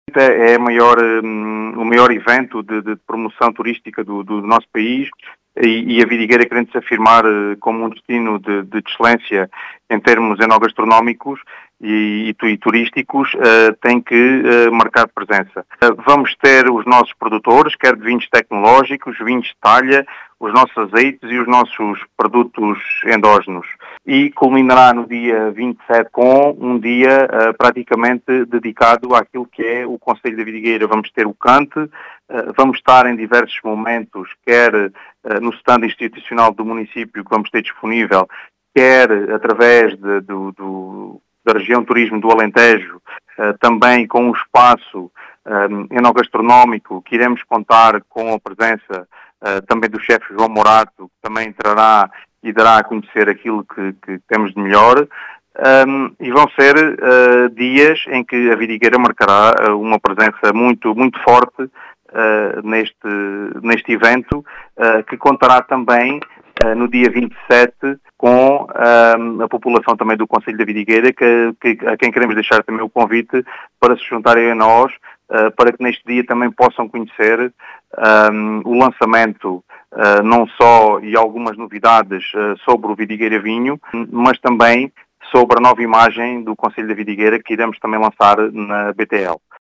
As explicações são do presidente da Câmara de Vidigueira, Ricardo Bonito, que sublinha o programa do dia de hoje.